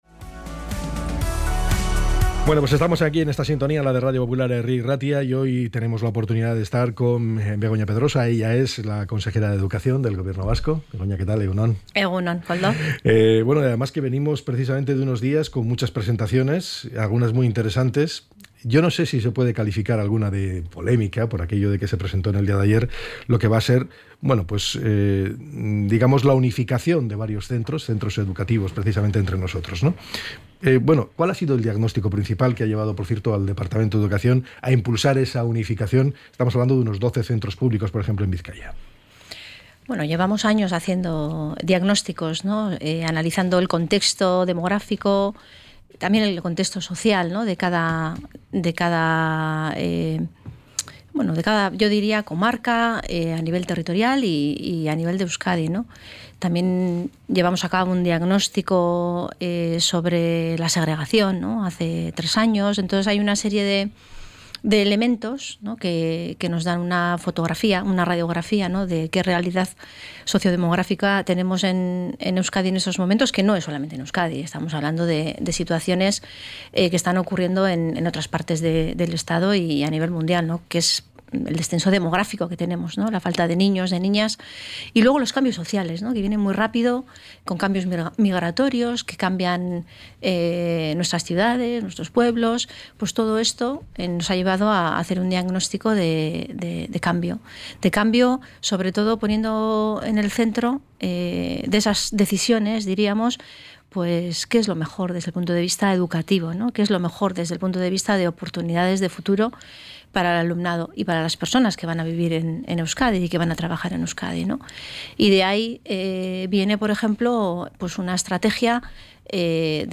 ENTREV.-BEGONA-PEDROSA.mp3